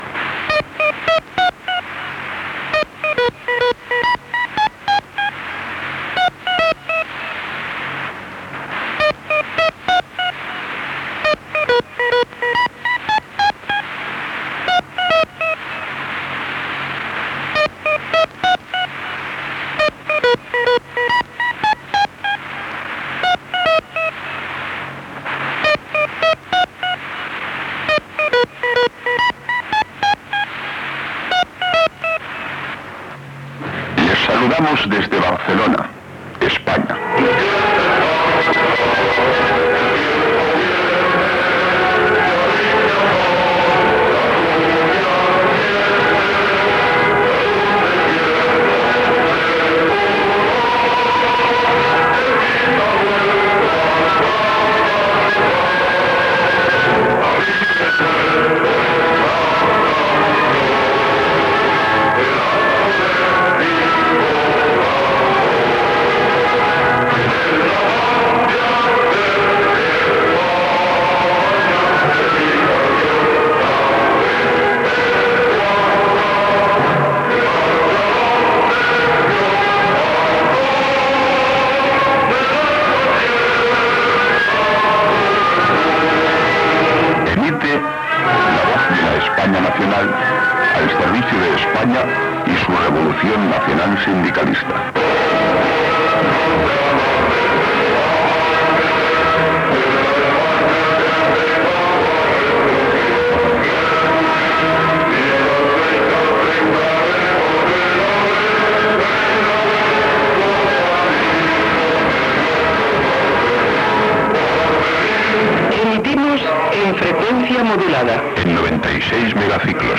Sintonia, inici d'emissió, sumari informatiu (segrest del diari Alcazar, conflicte Grupo Mundo, Joan Pau II), indicatiu, "Radiocrónica" (viatge del president Tarradellas i segrest de l'Alcazar).
Informatiu